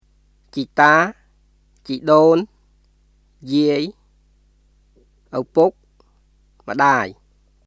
[チー・ター、チー・ドーン（ジアイ）、アウプック、ムダーイ　ciːtaː,　ciːdoːŋ(yiˑəi),　ʔəwpʊk,　mdaːi]